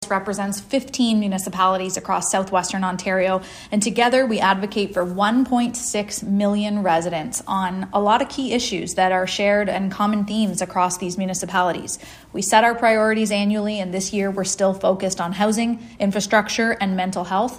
She spoke to NorfolkToday about the importance of this leadership role and what it means for the region.